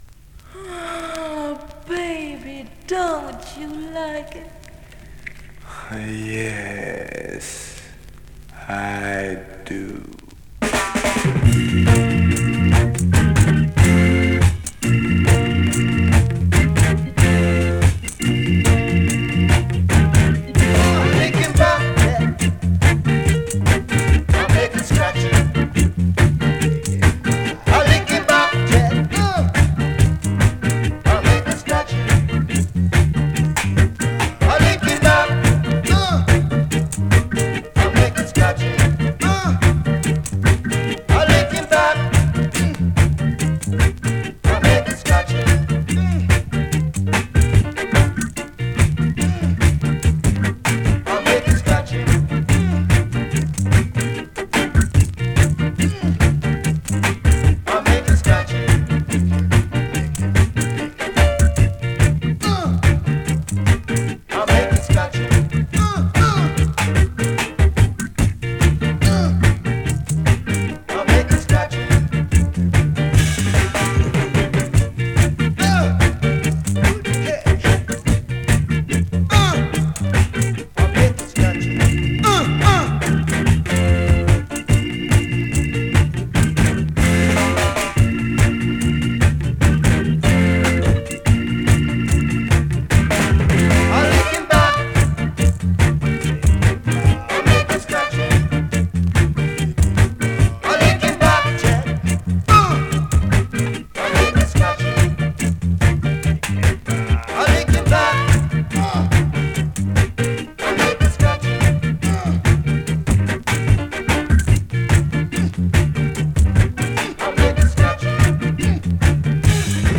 スリキズ、ノイズかなり少なめの